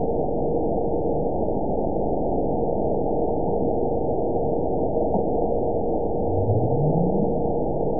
event 920120 date 02/24/24 time 02:32:46 GMT (1 year, 2 months ago) score 9.70 location TSS-AB02 detected by nrw target species NRW annotations +NRW Spectrogram: Frequency (kHz) vs. Time (s) audio not available .wav